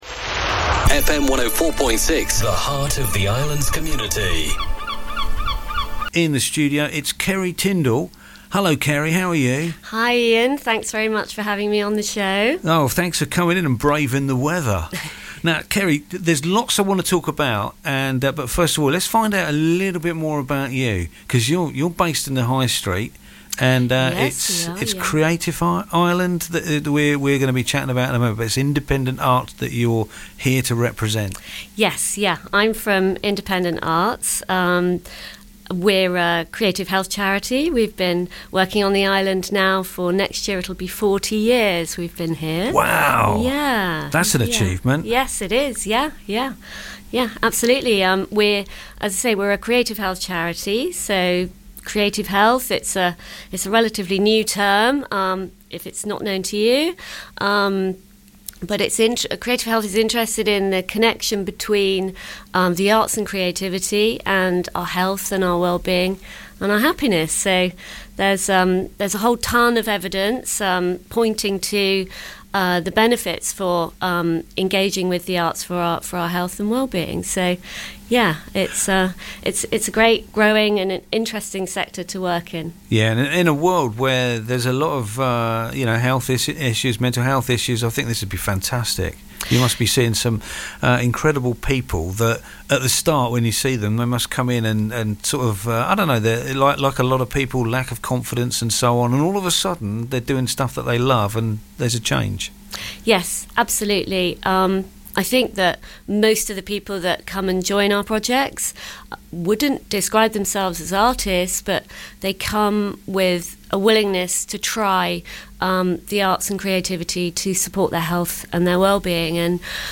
Vectis Interviews 2026